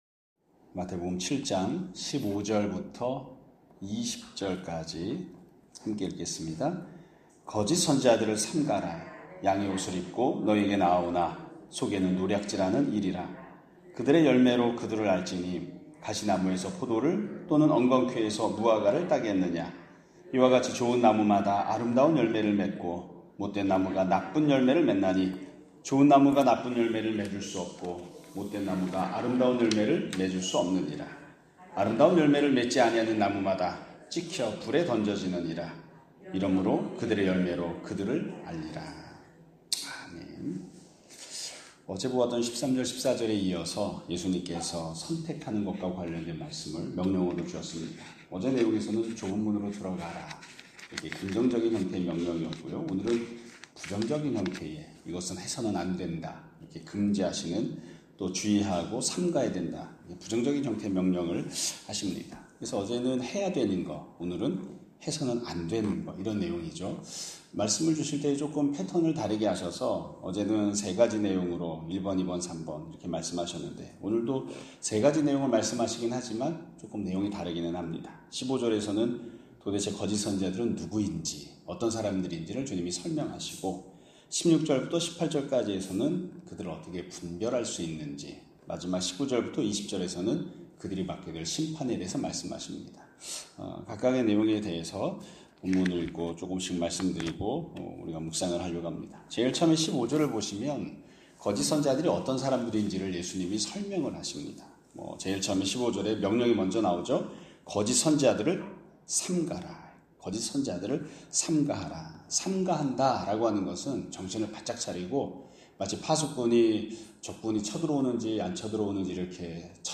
2025년 7월 1일(화 요일) <아침예배> 설교입니다.